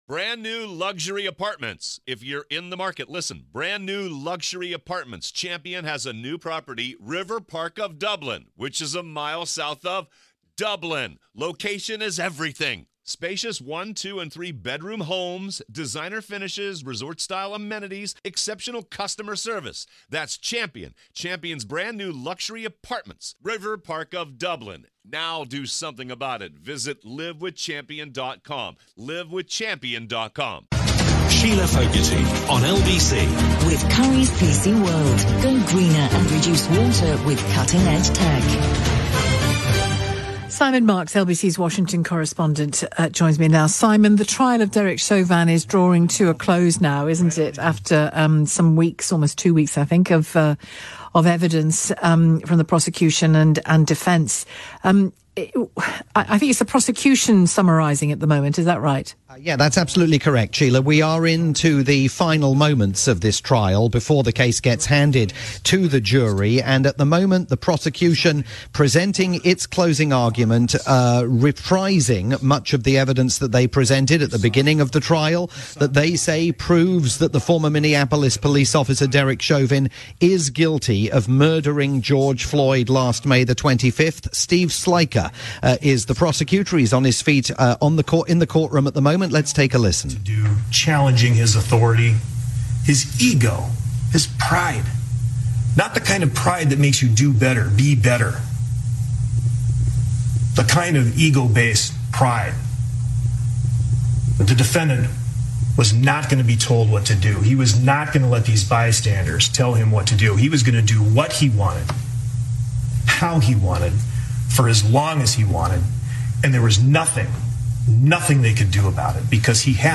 live report for Shelagh Fogarty's programme on the UK's LBC.